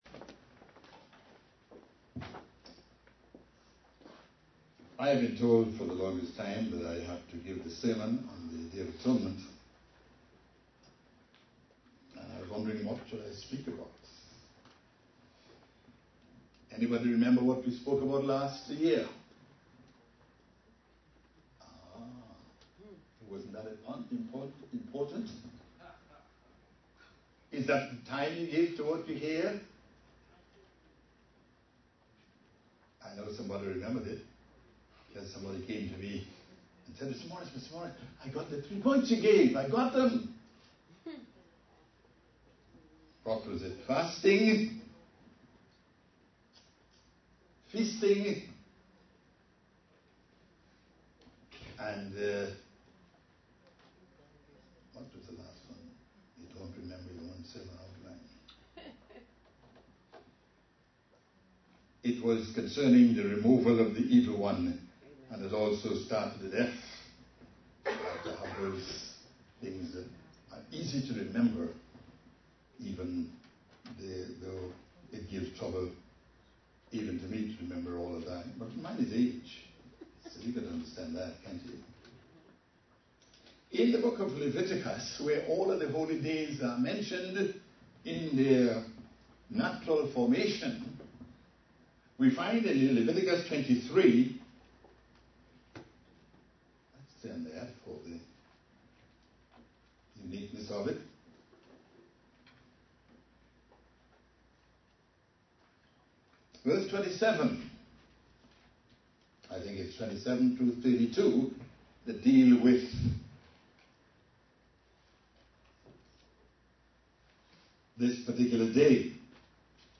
Given in New York City, NY
UCG Sermon Studying the bible?